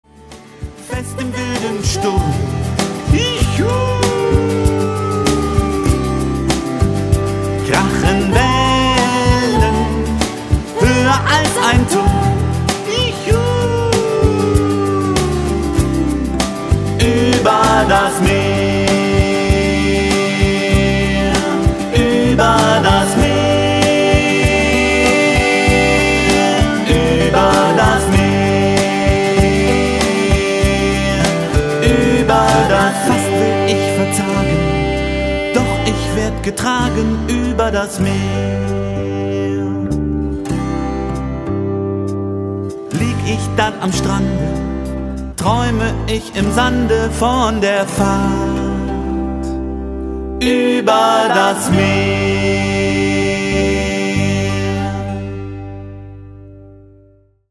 In fünfzehn schwungvollen Liedern begegnet
Zusammen mit Kindern singt er von kleinen Schiffen,